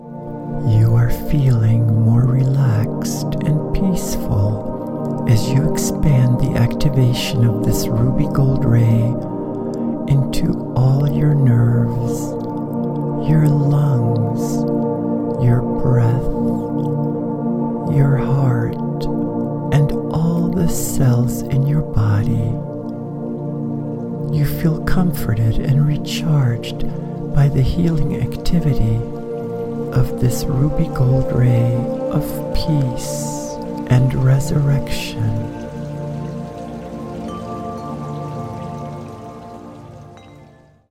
guided meditation